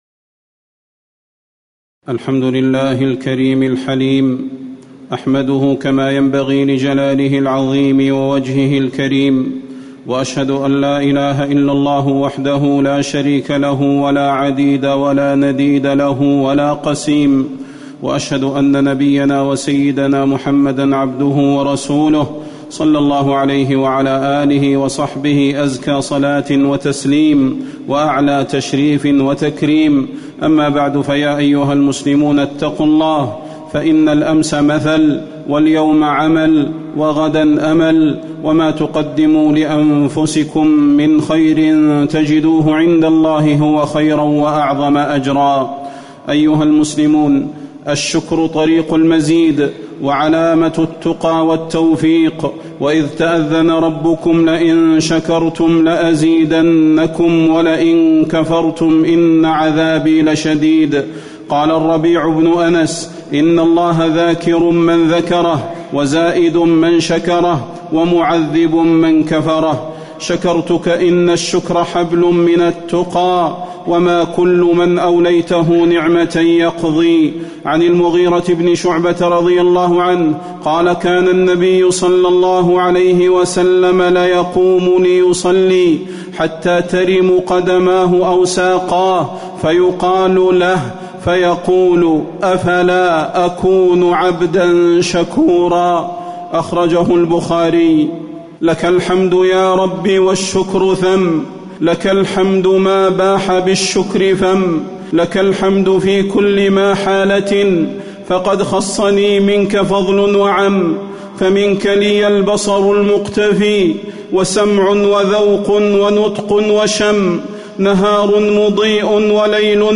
تاريخ النشر ١٦ شعبان ١٤٣٨ هـ المكان: المسجد النبوي الشيخ: فضيلة الشيخ د. صلاح بن محمد البدير فضيلة الشيخ د. صلاح بن محمد البدير بالشكر تدوم النعم